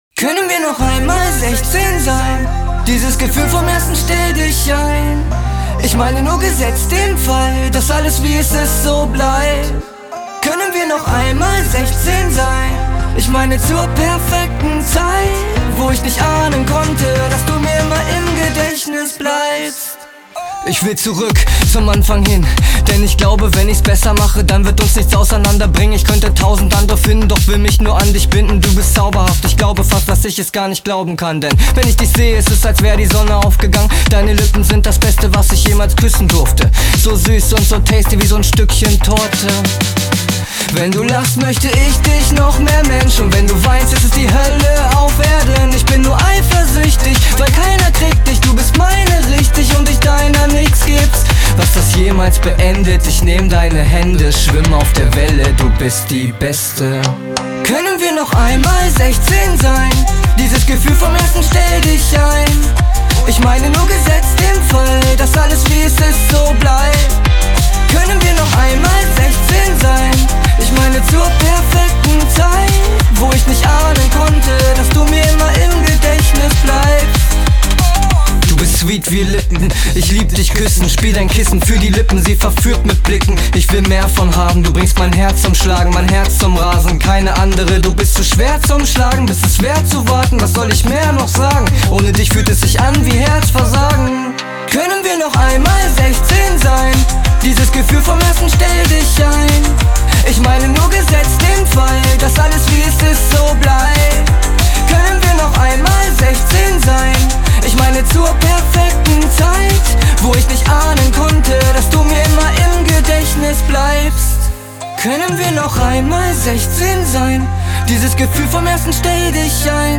KI-Deutsch Rap / KI-Hip Hop Song
Ein moderner Rap & Gesangssong inkl. Autotune
Deutsch Rap / Hip Hop Song: